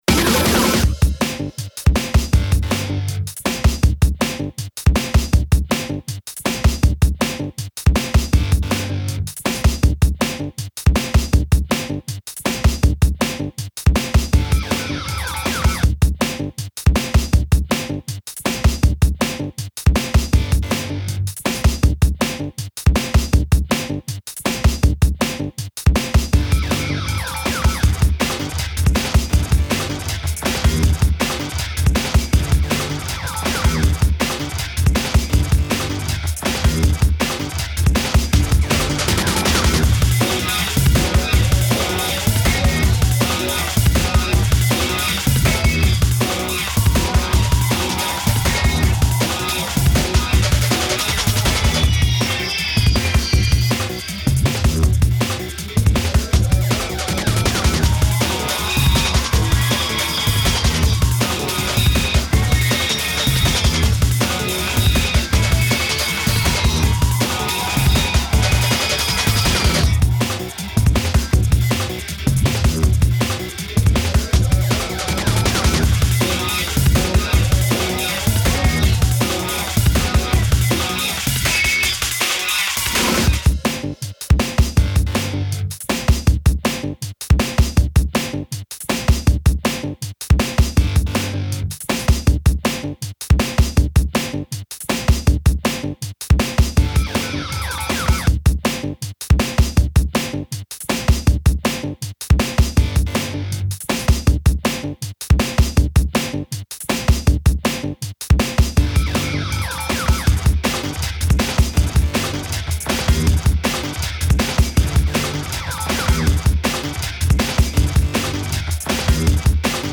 BGM(MP3)